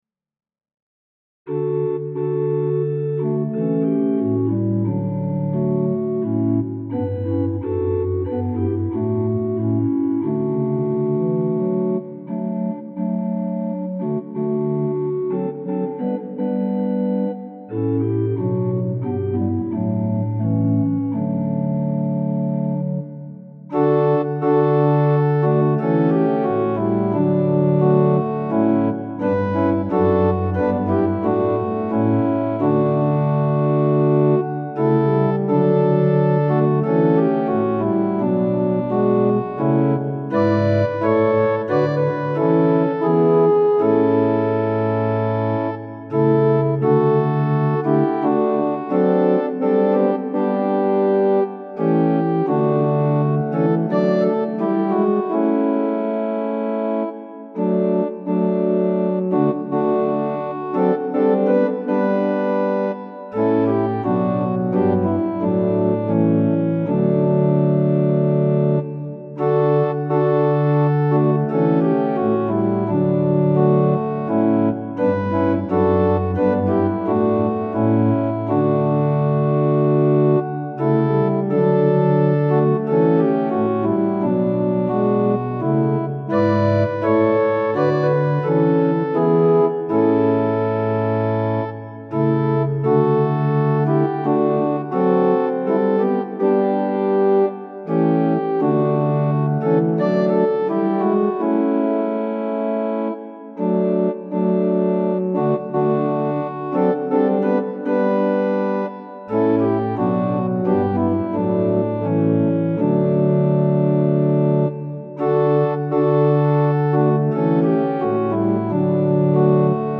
♪賛美用オルガン伴奏音源：
・柔らかい音色部分は前奏です
・はっきりした音色になったら歌い始めます